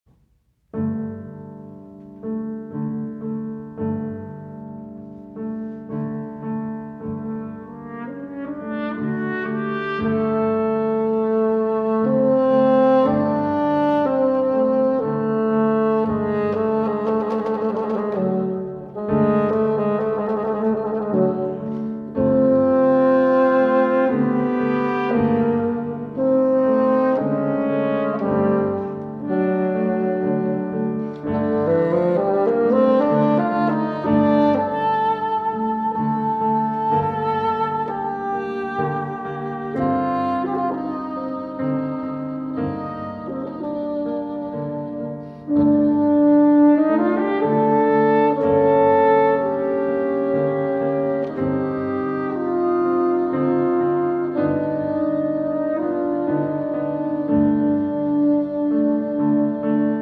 for Horn, Bassoon, and Piano